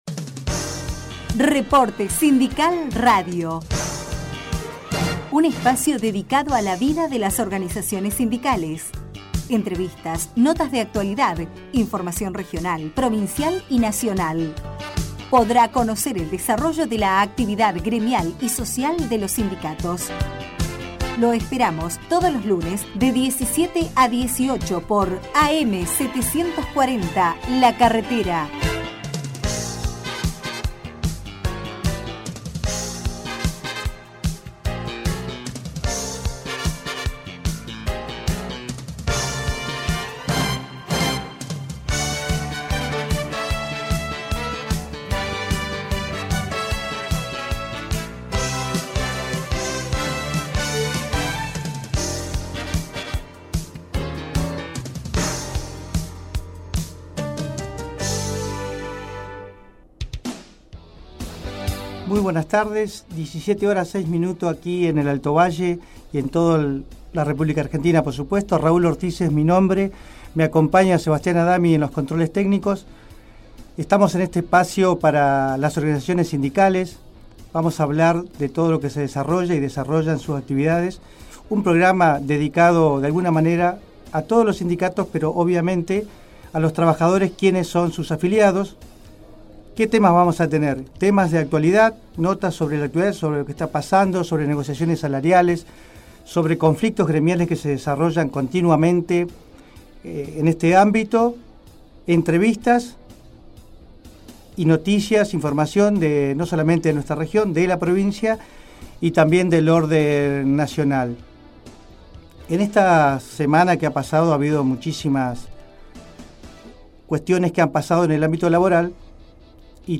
Programa Emitido Por Am740La Carretera Todos Los Lunes De 17 a 18